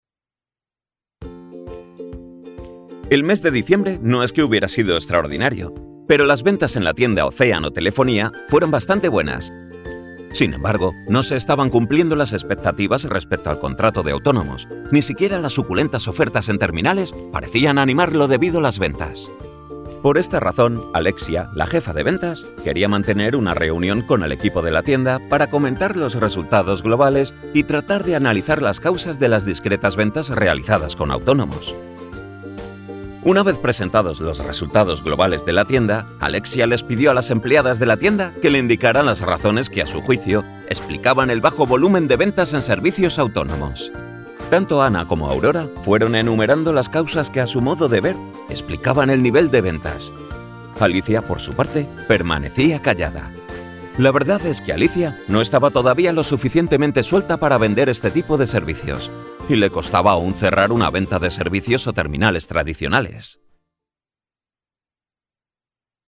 Volwassen, Warm, Speels, Stoer, Veelzijdig
E-learning